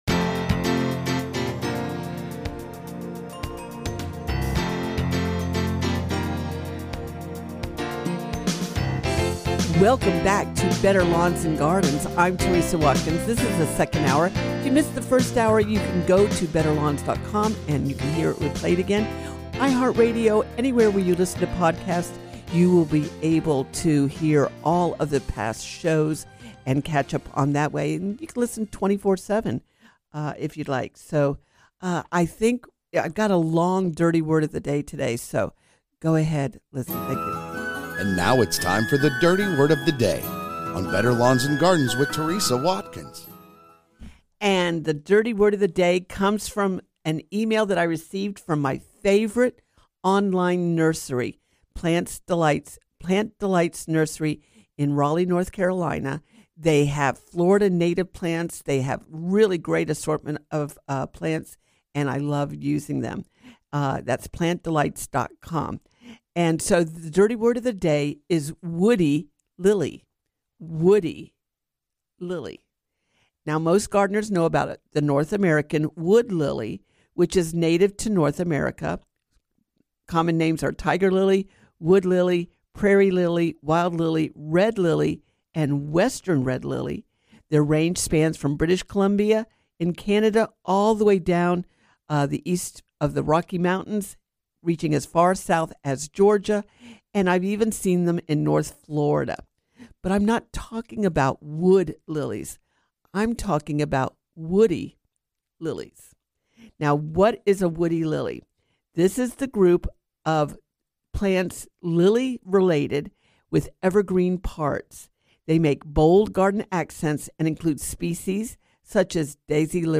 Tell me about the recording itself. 1 S2 Ep398: Better Lawns and Gardens - Hour 2 Fall Chores For The Landscape 39:32 Play Pause 3h ago 39:32 Play Pause Play later Play later Lists Like Liked 39:32 Better Lawns and Gardens Hour 2 – Coming to you from the Summit Responsible Solutions Studios.